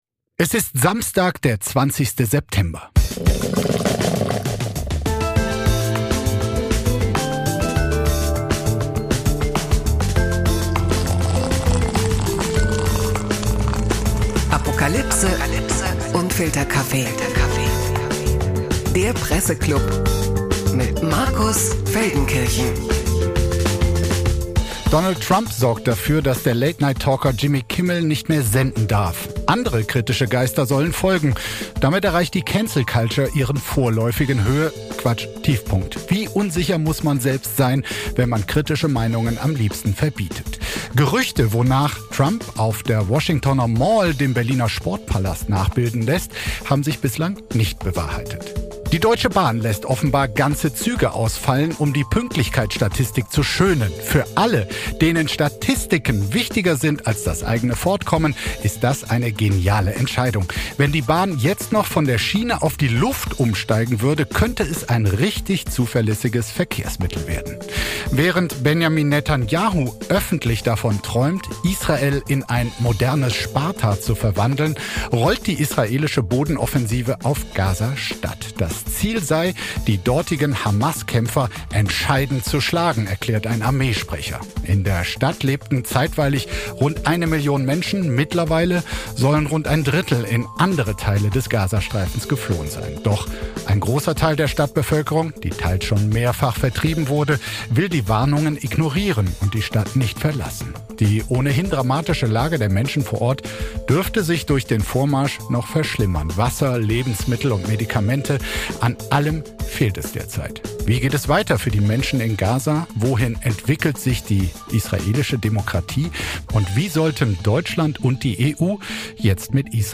Live aus Tel Aviv zugeschaltet